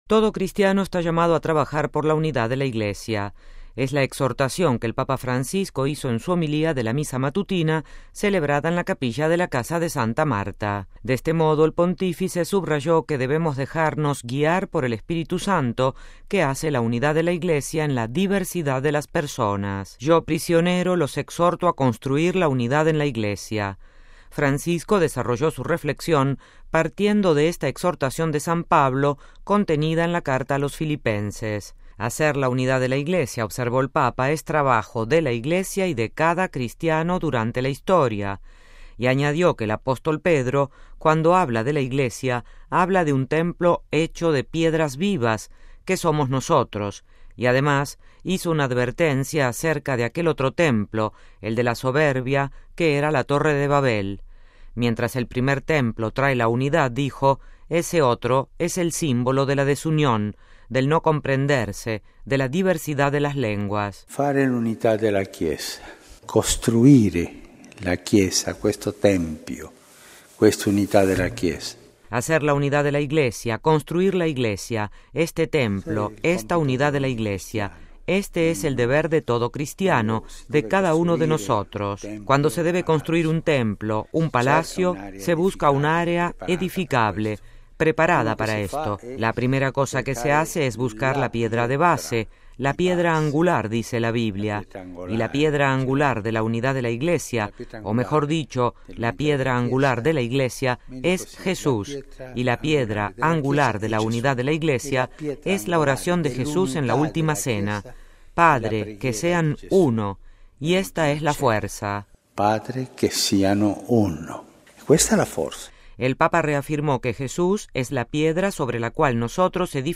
Es la exhortación que el Papa Francisco hizo en su homilía de la Misa matutina celebrada en la capilla de la Casa de Santa Marta. De este modo, el Pontífice subrayó que debemos dejarnos guiar por el Espíritu Santo que hace la unidad de la Iglesia en la diversidad de las personas.